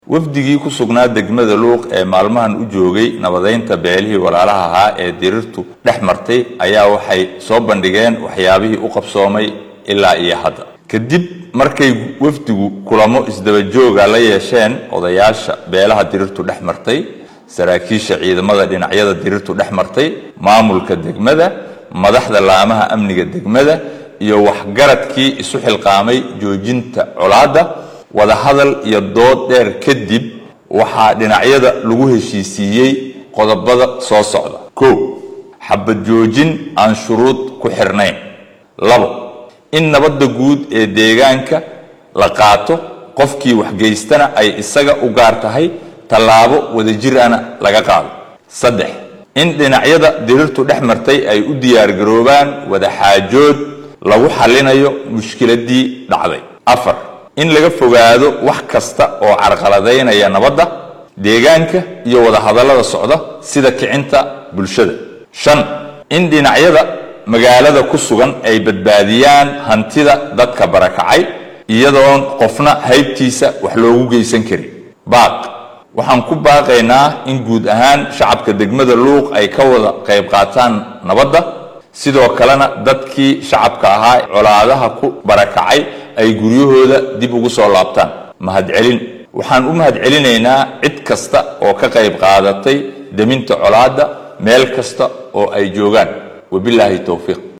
Madaxweyne ku xigeenka 1-aad ee Maamul goboleedka Soomaaliyeed ee Jubbaland Maxamuud Sayid Aadan iyo mas’uuliyiin ka tirsan dowladda federaalka Soomaaliya ayaa dhinacyadii ku dagaalamay degmada Luuq ugu baaqay xabad joojin shuruud la’aan ah iyo in la badbaadiyo hantida shacabkii barakacay. Wasiir dowlaha wasaaradda deegaanka iyo isbeddelka cimillada ee Soomaaliya Axmad Cumar Maxamad ayaa ka warbixiyay qodobbo hordhac ah oo guddigan colaaddii dhacday ka soo sareen.